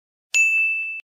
Ping - Sound Effect